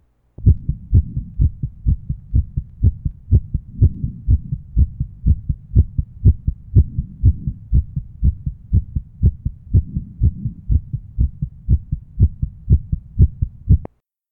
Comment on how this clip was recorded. RUSB (Channel A) LUSB (Channel B) LLSB (Channel C) Apex (Channel D) %s1 / %s2